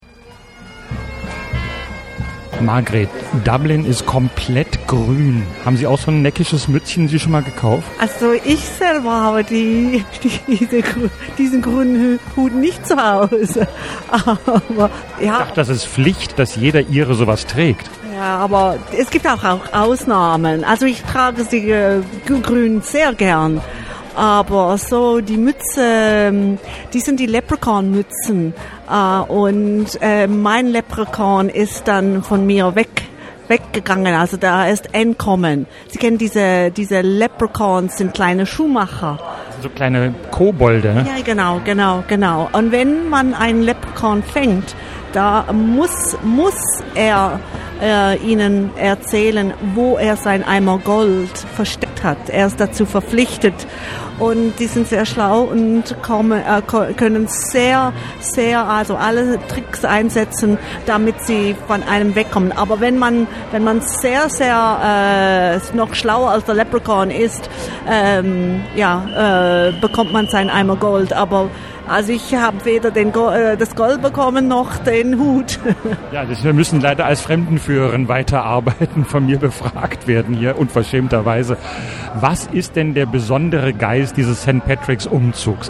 Selbstversuch am St. Patricks Day am Rande der Dubliner Parade. Bemüht, dem Mythos des Volksheiligen-Tages auf die Spur zu kommen.